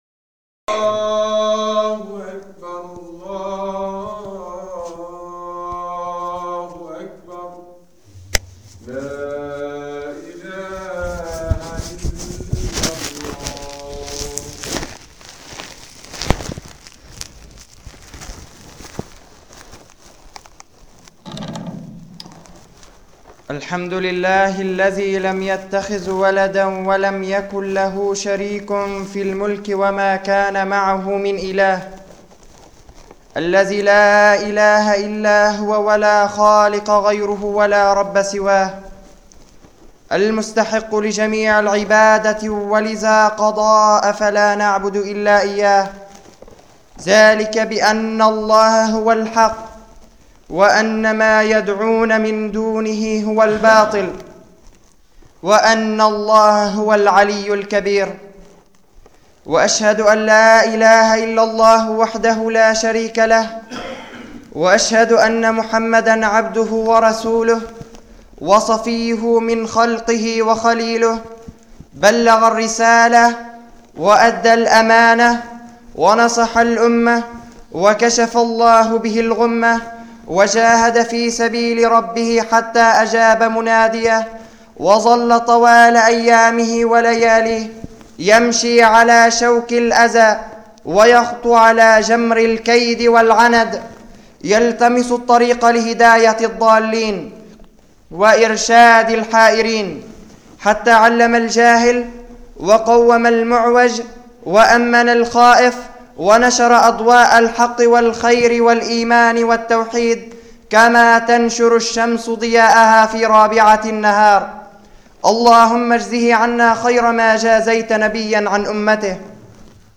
[خطبة جمعة] أعظم الدعاة ٢
المكان: مسجد إيزال-الضنية الموضوع: أعظم الدعاة ٢ تحميل